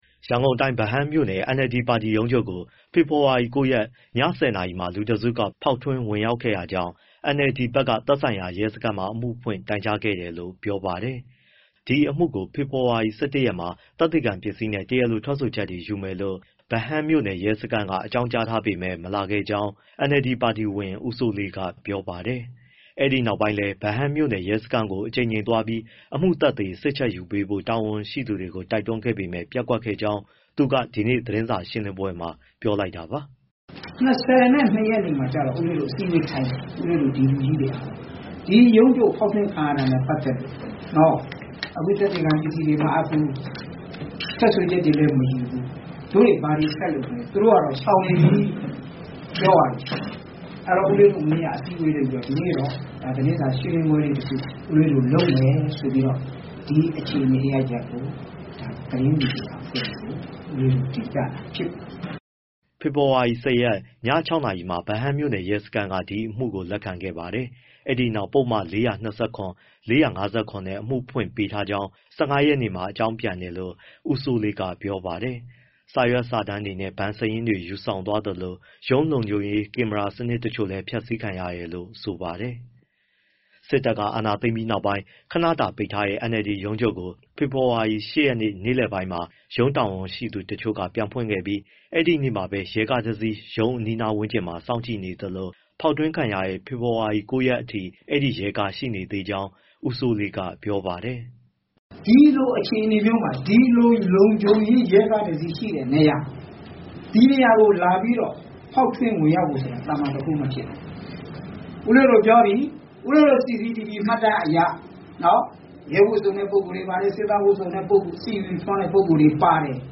ပါတီရုံးချုပ်ညပိုင်းဖောက်ထွင်းဝင်ရောက်မှု NLD သတင်းစာရှင်းလင်း